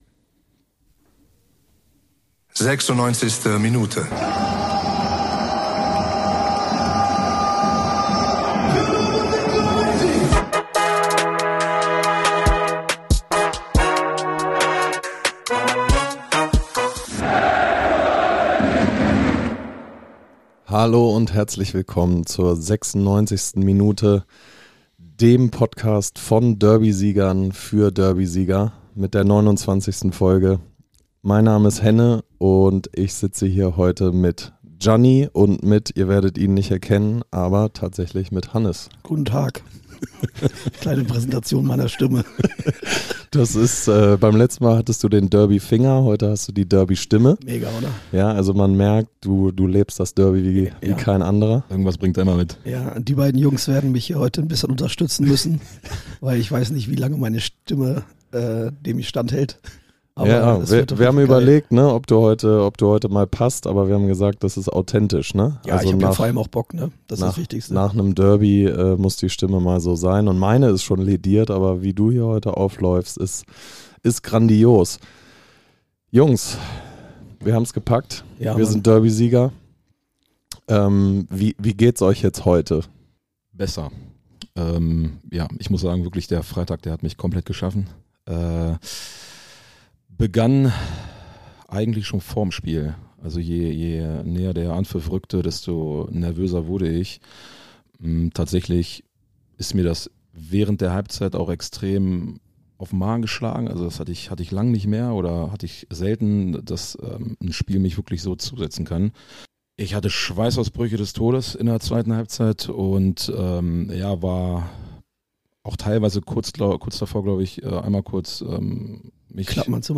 In Folge 29 feiern wir den zweiten Derbysieg in dieser Saison. Mit wenig bis kaum Stimme schwelgen wir in den Emotionen und küren das Spiel zu einem der unvergesslichsten Derbys!